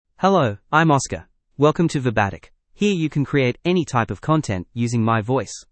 Oscar — Male English (Australia) AI Voice | TTS, Voice Cloning & Video | Verbatik AI
Oscar is a male AI voice for English (Australia).
Voice sample
Oscar delivers clear pronunciation with authentic Australia English intonation, making your content sound professionally produced.